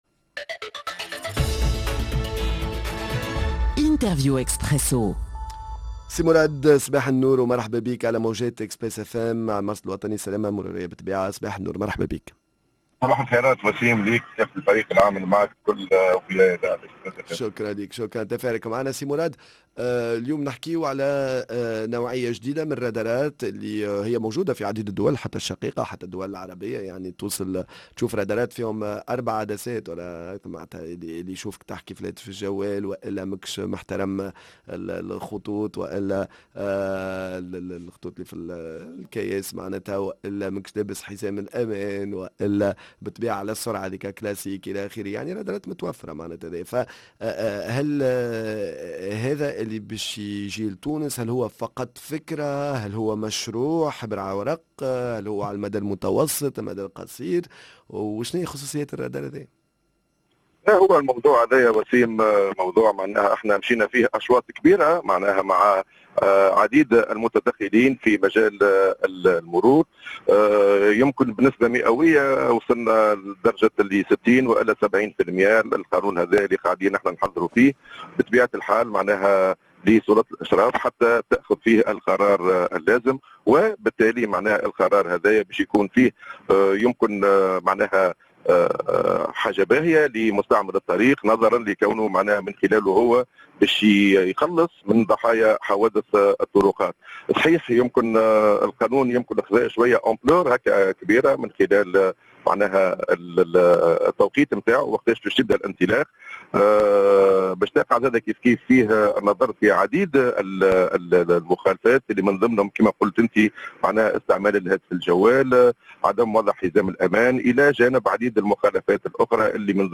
من بينها استعمال الهاتف الجوّال: هذه المخالفات المروريّة سيلتقطها “الرّادار” بصفة آليّة، معانا عبر الهاتف